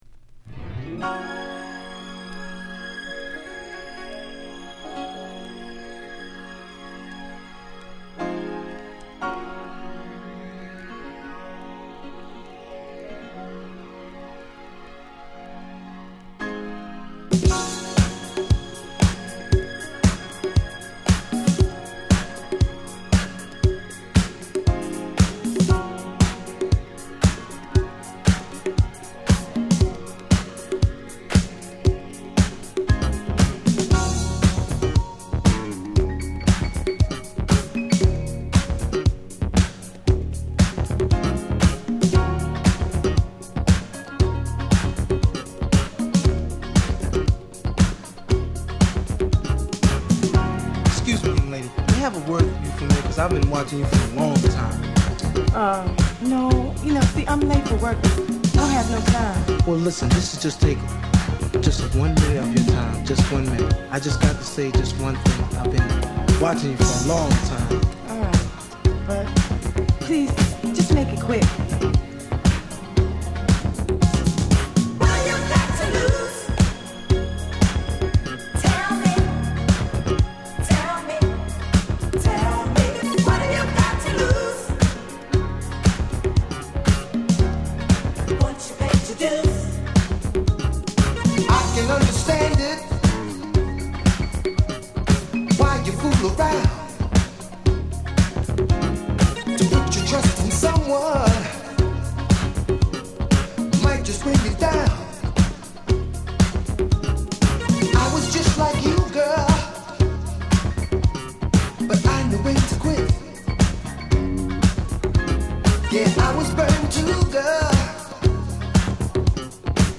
DISCO FUNK
Great Urban Funk